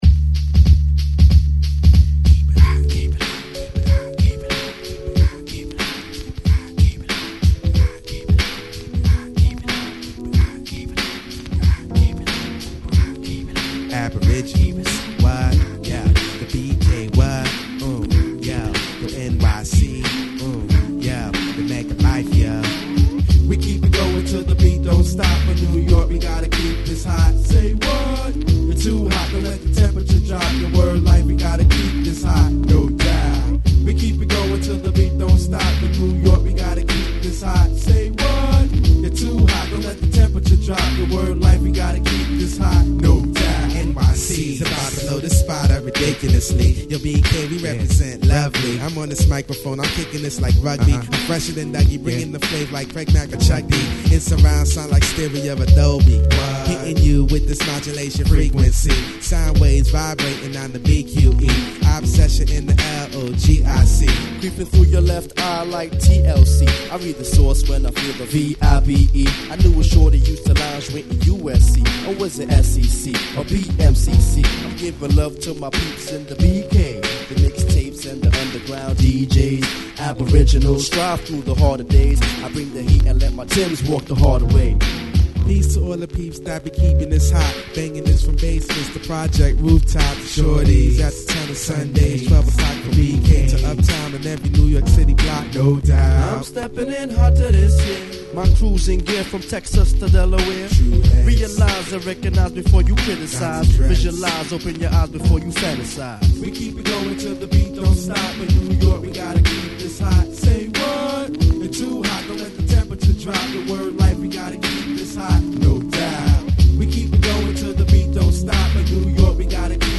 Mid 90's Underground Hip Hop Classic!!
※細かい擦り傷がありますがノイズは余り気になりません。
※この盤からの録音ですので「試聴ファイル」にてご確認下さい。